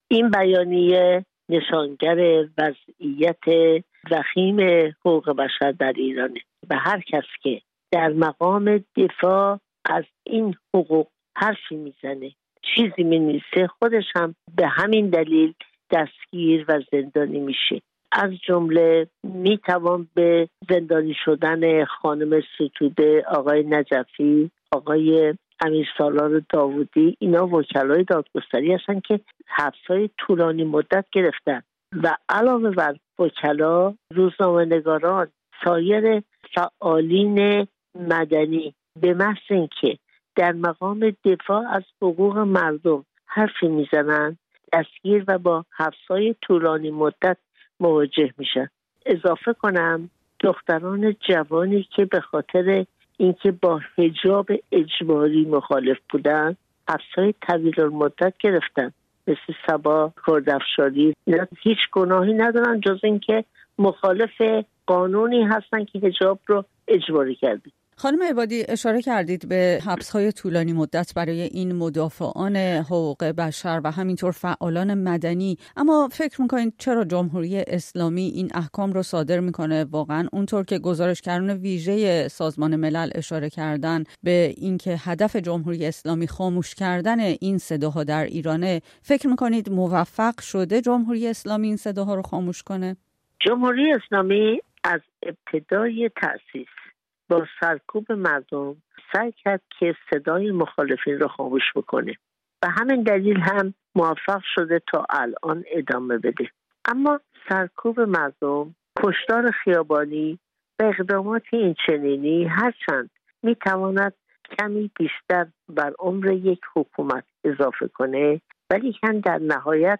کارشناسان سازمان ملل می‌گویند حکومت ایران از حبس طولانی‌مدت برای خاموش‌کردن صدای مدافعان حقوق بشر استفاده می‌کند. گفت‌وگویی را بشنوید با شیرین عبادی حقوقدان و برنده جایزه صلح نوبل.